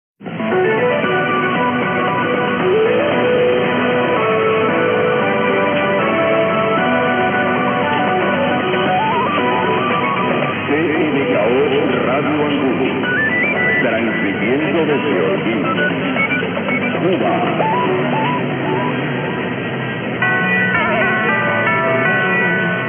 Audio clips with transcriptions of hourly radio station identifications.
Radio Angulo - "CMKO Radio Angulo, transmitiendo desde Holguín, Cuba," and chimes.